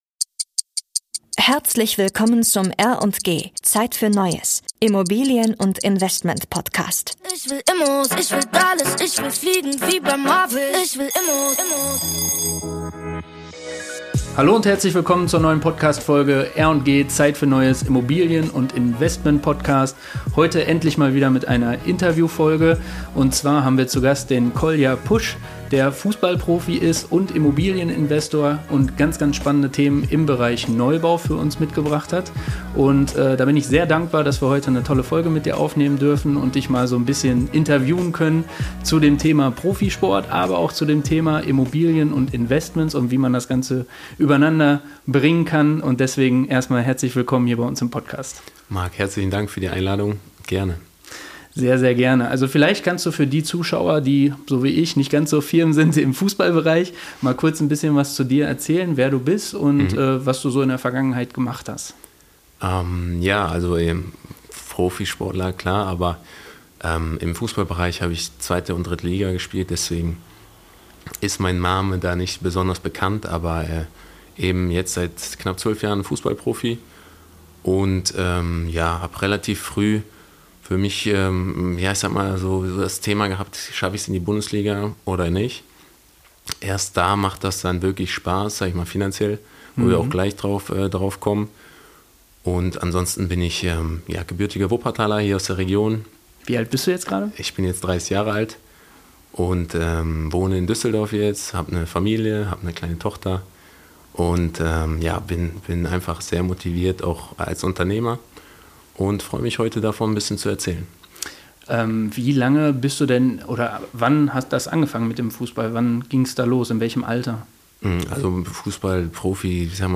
Doppelpack-Interview